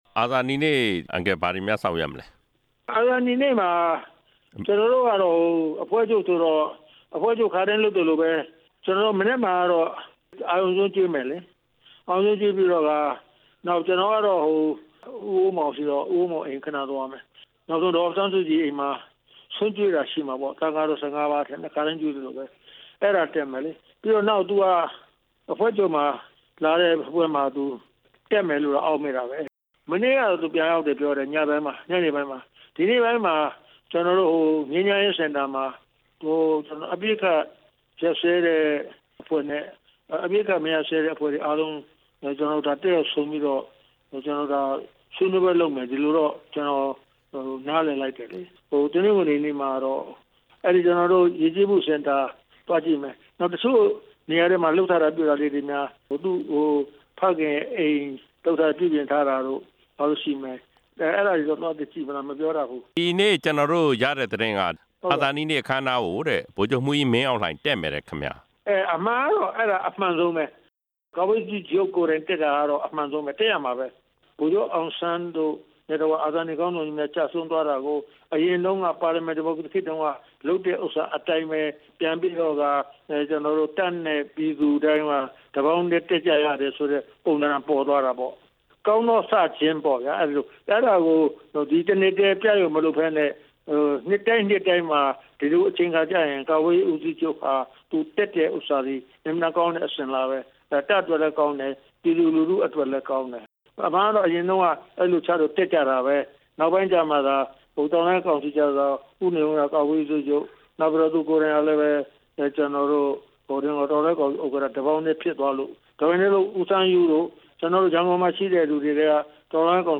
NLD နာယက ဦးတင်ဦးနဲ့ မေးမြန်းချက်